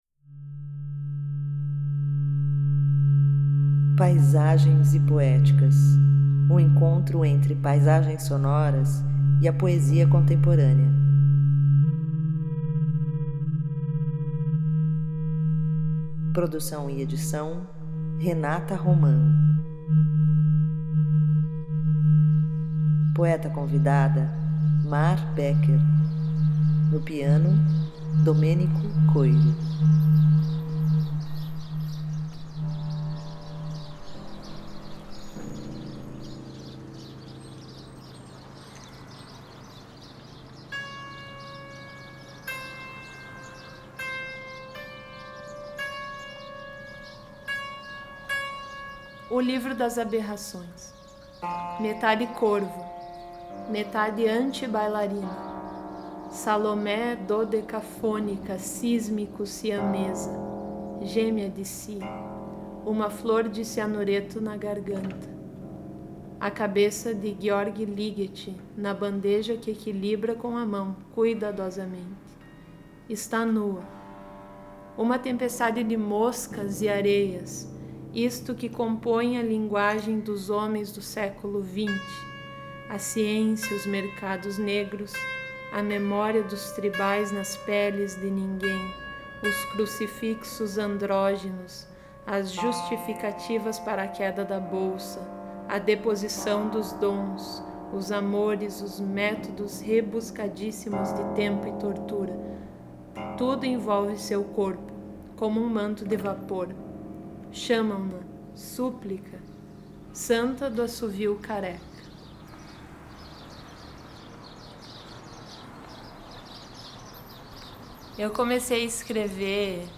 Paisagens e Poéticas– Programa criado inicialmente para a Mobile Radio na 30ª Bienal de Artes de São Paulo, em 2012. Propõe o encontro entre paisagens sonoras e a poesia contemporânea.